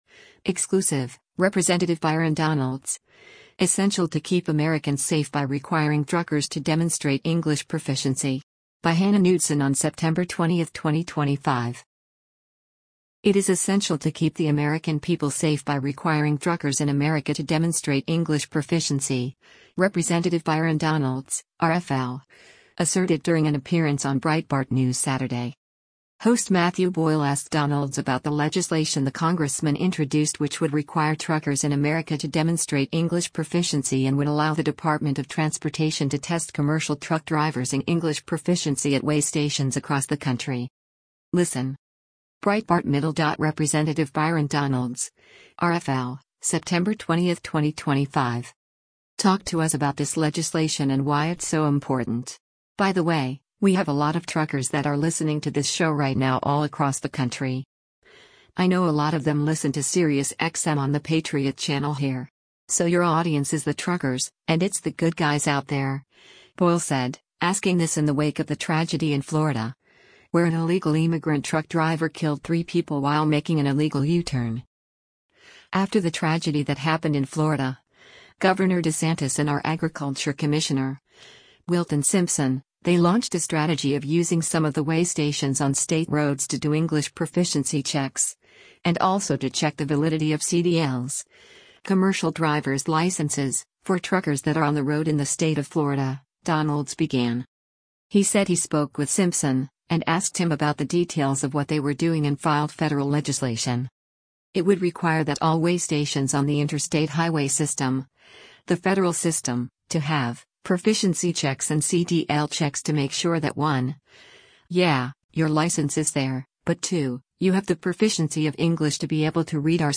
It is essential to keep the American people safe by requiring truckers in America to demonstrate English proficiency, Rep. Byron Donalds (R-FL) asserted during an appearance on Breitbart News Saturday.